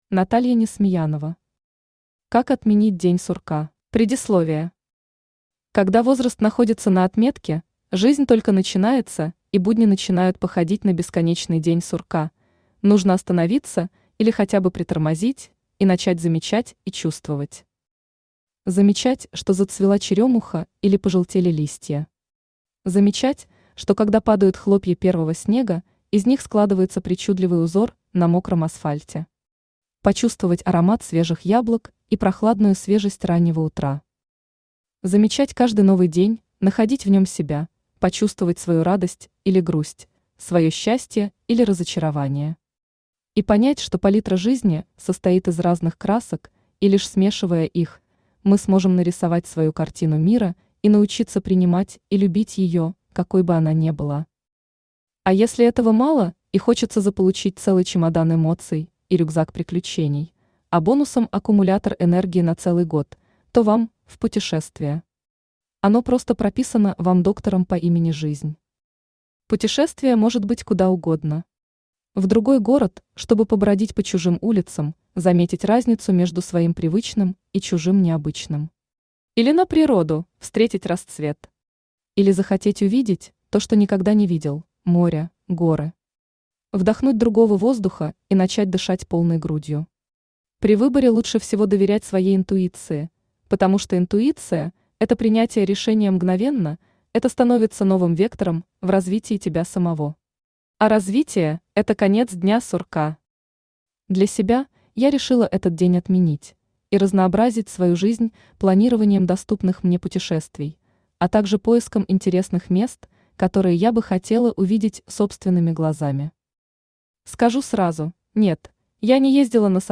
Aудиокнига Как отменить «день сурка»… Автор Наталья Несмеянова Читает аудиокнигу Авточтец ЛитРес.